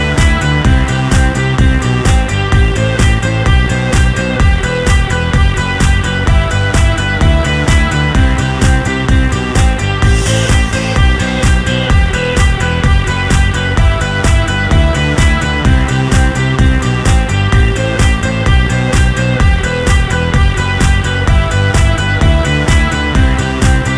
no Backing Vocals R'n'B / Hip Hop 3:24 Buy £1.50